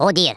Worms speechbanks
ohdear.wav